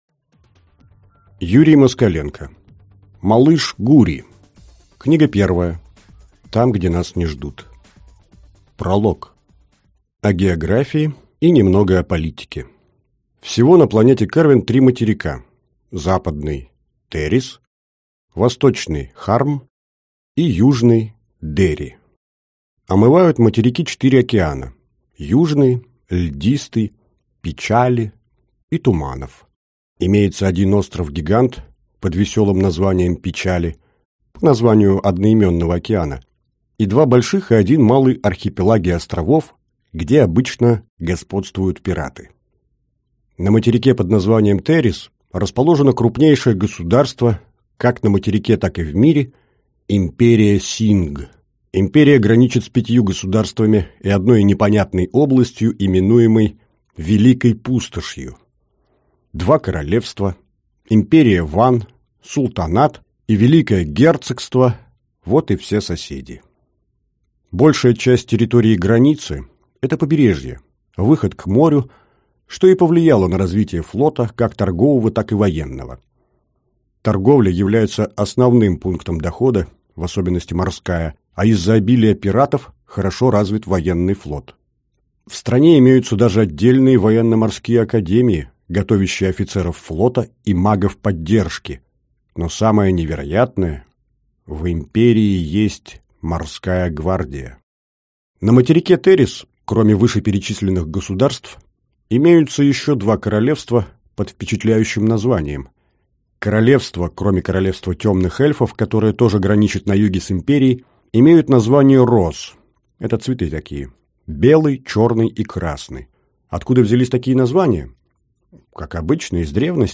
Аудиокнига Малыш Гури. Книга первая. Там, где нас не ждут…